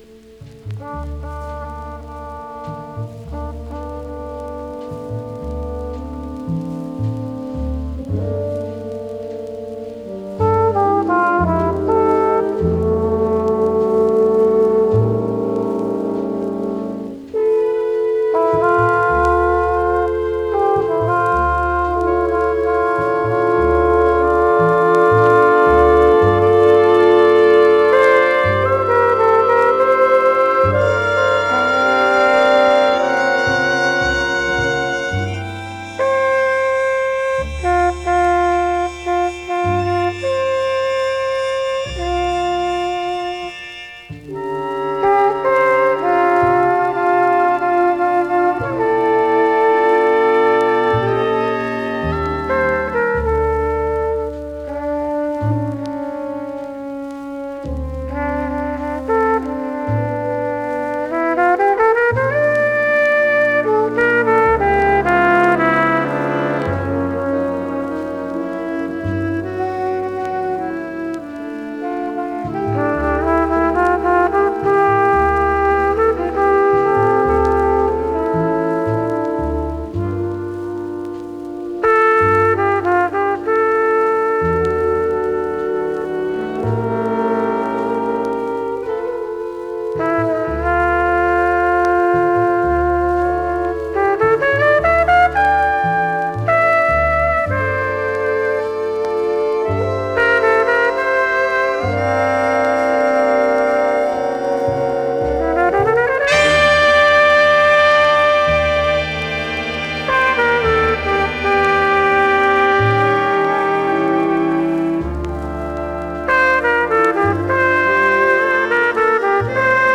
全体的に軽いチリチリ・ノイズ。
MONO盤です。